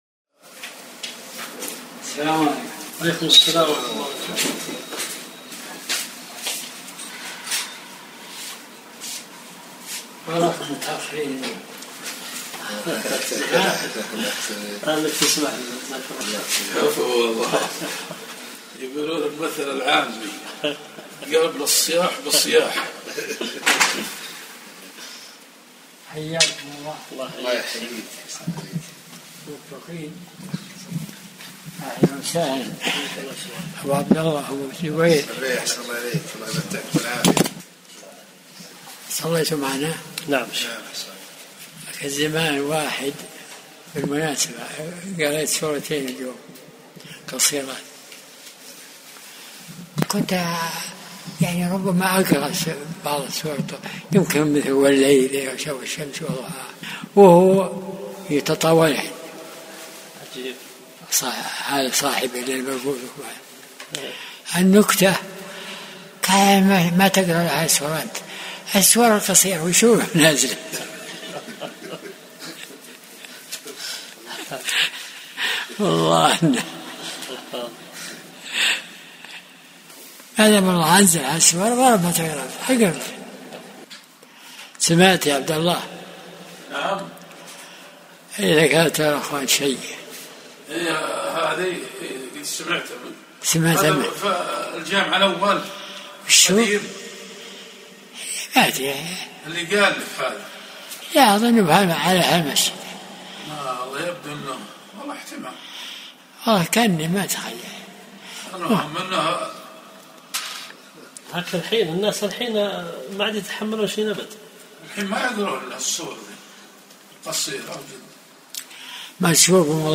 درس الأحد 58